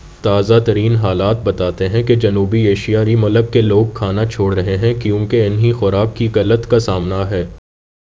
Spoofed_TTS/Speaker_11/271.wav · CSALT/deepfake_detection_dataset_urdu at main